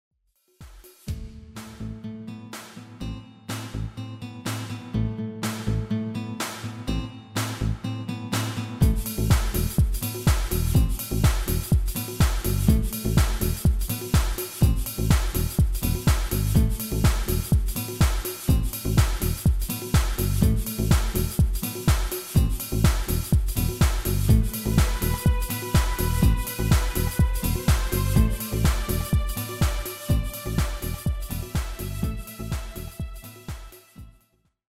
Demo/Koop midifile
Genre: Dance / Techno / HipHop / Jump
- GM = General Midi level 1
- Géén vocal harmony tracks